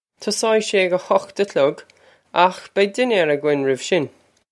Pronunciation for how to say
Tus-oh-ig shay air ah hukht ah klug, okh beg din-yare og-inn rih-v shin.
This is an approximate phonetic pronunciation of the phrase.
This comes straight from our Bitesize Irish online course of Bitesize lessons.